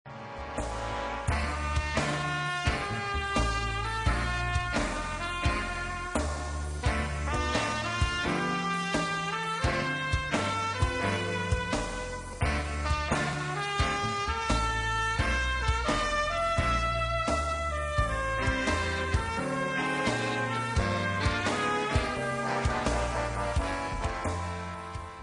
Trumpet Sounds of My Students:
His trumpet is a gold plated Flip Oakes Wild Thing with a 13B4a Schilke mouthpiece.